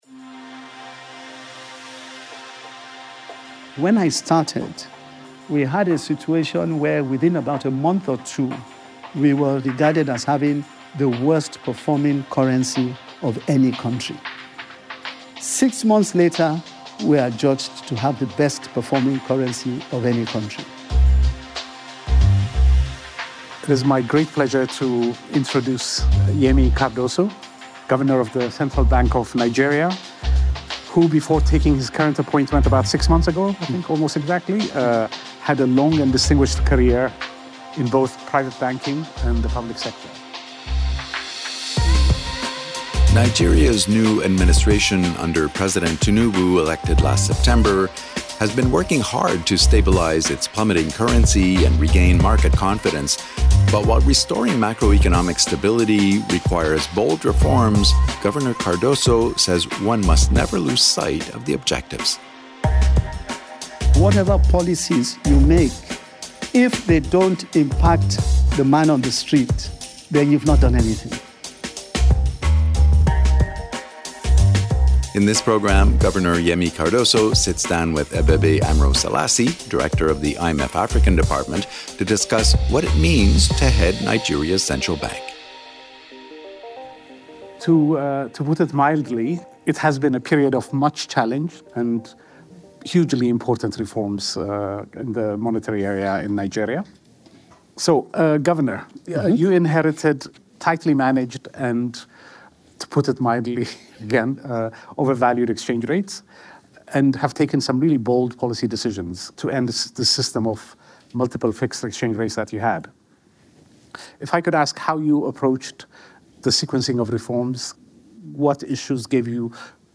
Nigeria’s new administration has set out on an ambitious reform path to stabilize its currency, regain market confidence, and tame inflation. In this podcast, Governor Olayemi Cardoso and IMF Africa Department head, Abebe Aemro Selassie discuss the role of Nigeria’s central bank in restoring macroeconomic stability. The conversation took place as part of the Governor Talks series held during the IMF-Word Bank Spring Meetings.